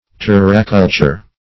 Search Result for " terraculture" : The Collaborative International Dictionary of English v.0.48: Terraculture \Ter"ra*cul`ture\, n. [L. terra the earth + cultura.]